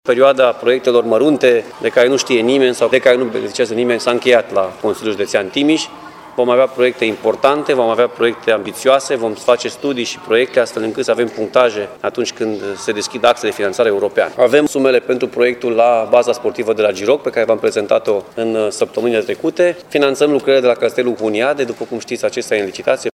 Liderul CJ Timiș mai dă asigurări și că investițiile se vor concentra pe cele majore, cu impact. În domeniul drumurilor județene, prioritate vor avea cele intens circulate.